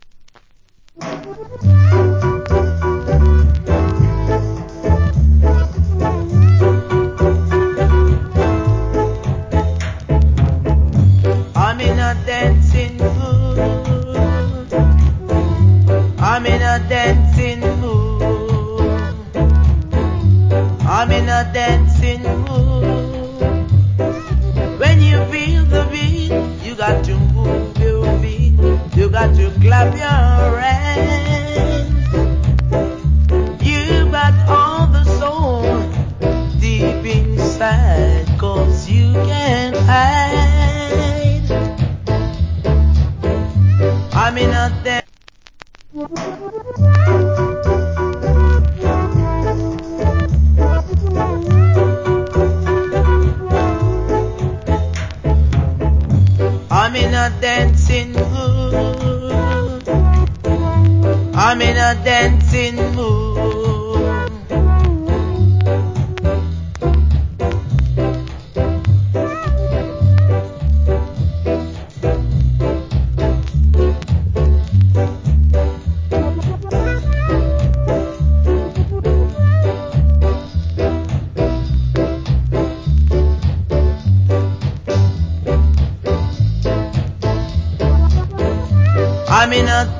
Foundation Rock Steady Vocal. Remixed.